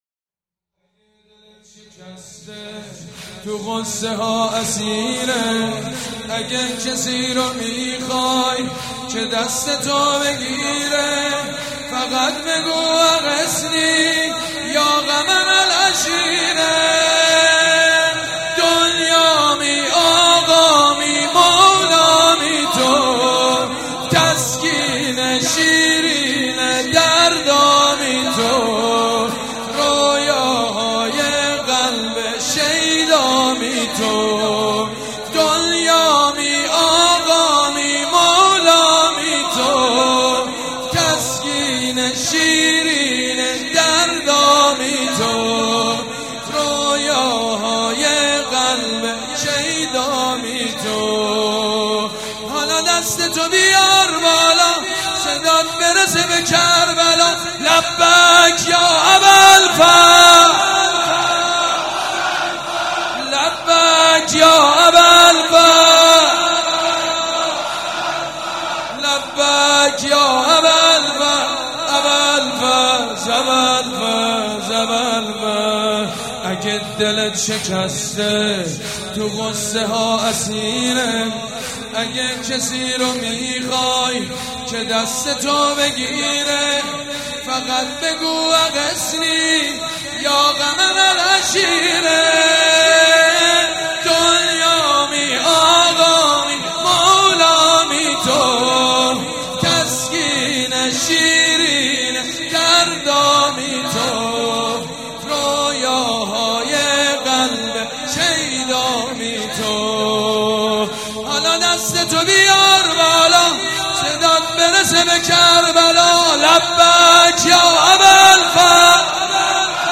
(زمینه جدید)